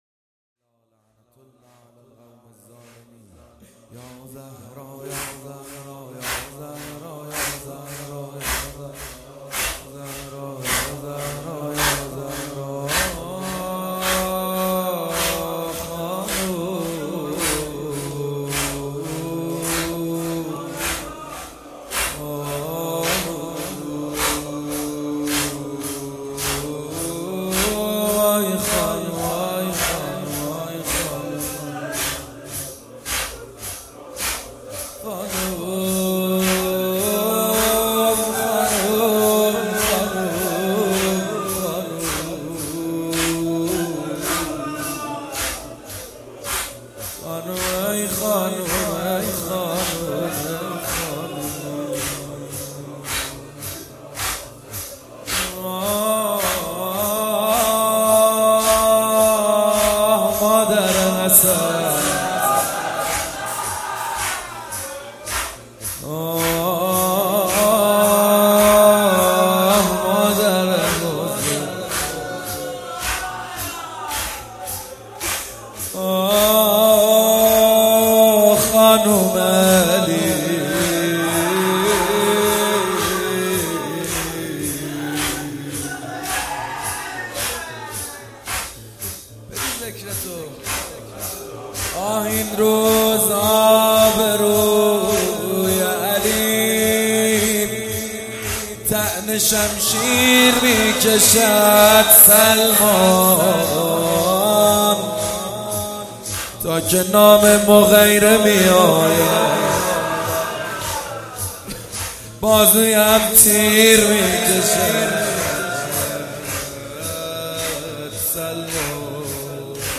شور اربعین
زمینه اربعین